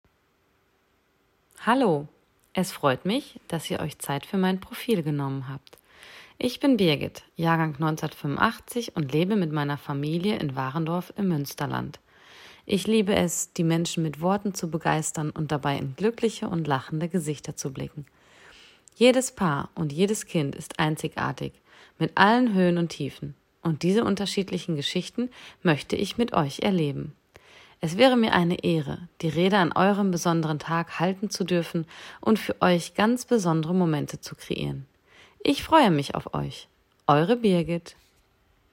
Stimmprobe